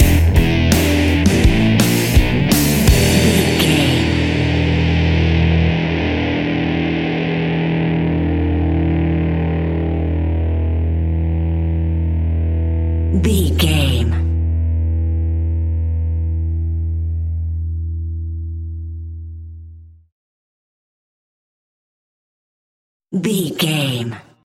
Epic / Action
Fast paced
Aeolian/Minor
hard rock
blues rock
Rock Bass
heavy drums
distorted guitars
hammond organ